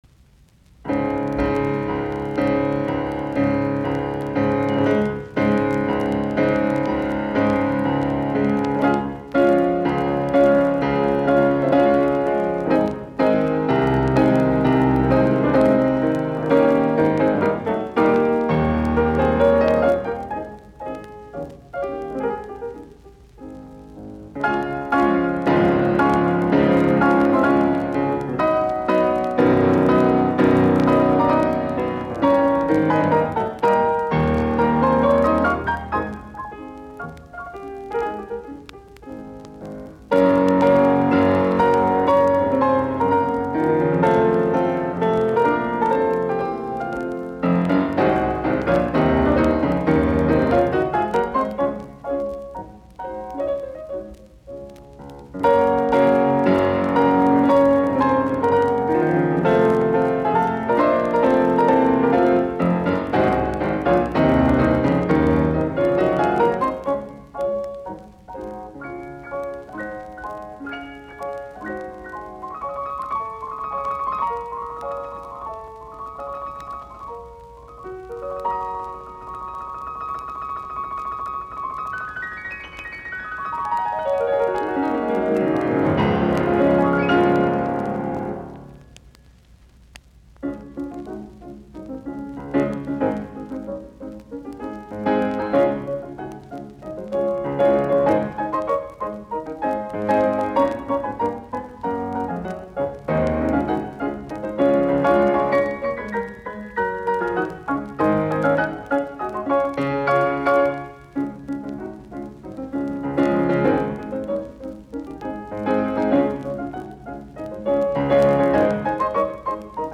Nro 6, Tempo giusto
Soitinnus: Piano.